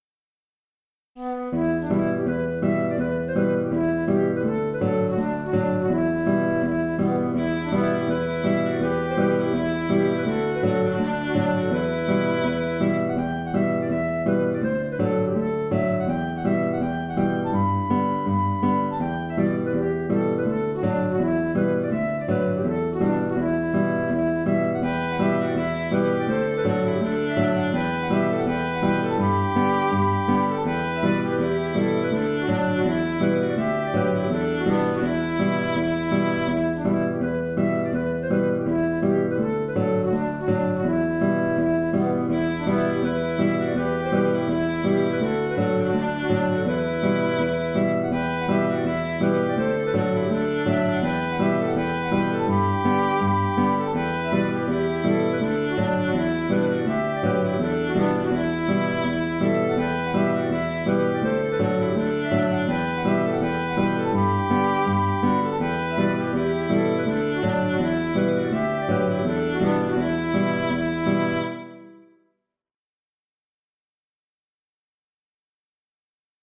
Scottish